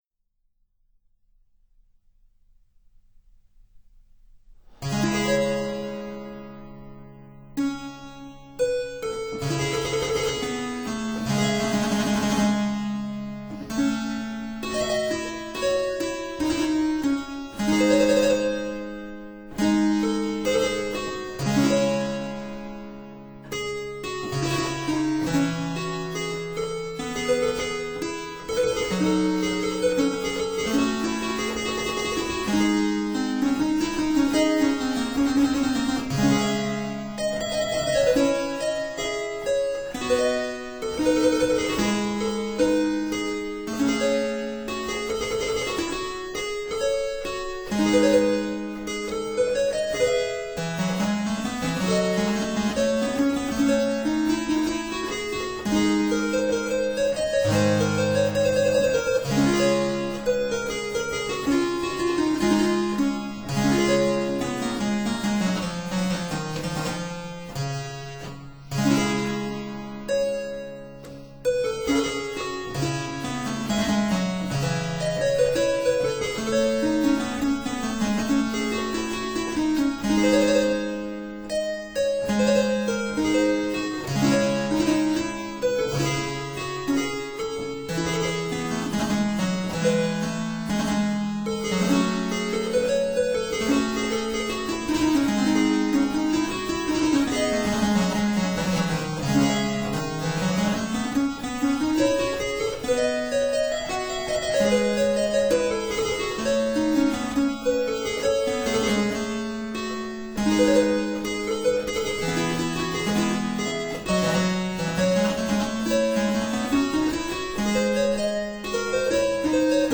virginal, harpsichord
Virginal is a Ruckers copy
Harpsichord is a copy of an Austrian instrument (c. 1680)